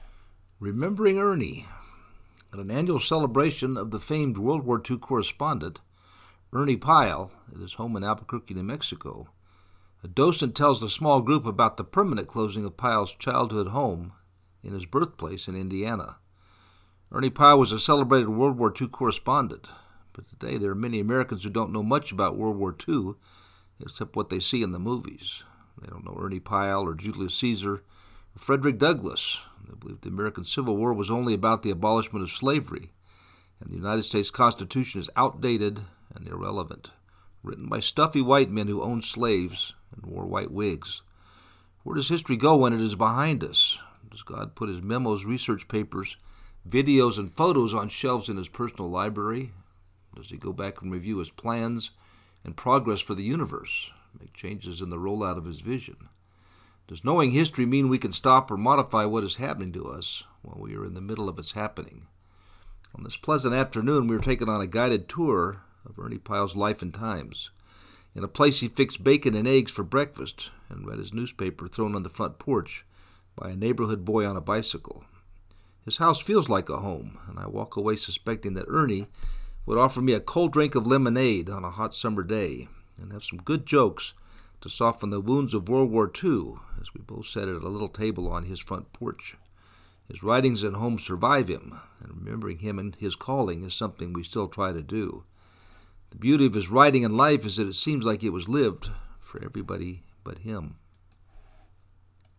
At an annual celebration of the famed World War 2 correspondent, Ernie Pyle, at his home in Albuquerque, N.M., a docent tells the small group about the permanent closing of Pyle’s childhood home, in his birthplace,in Indiana.
On this pleasant afternoon, we are taken on a guided tour of Ernie Pyle’s life and times, in a place he fixed bacon and eggs for breakfast and read his newspaper thrown on the front porch by a neighborhood boy on a bicycle.